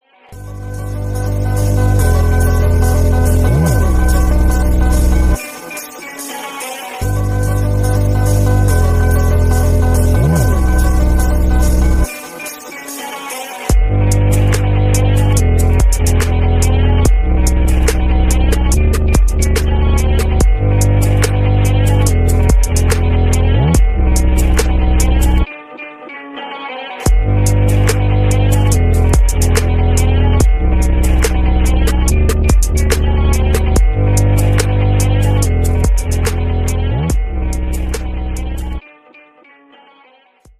• Качество: 192, Stereo
гитара
атмосферные
без слов
струнные
speed up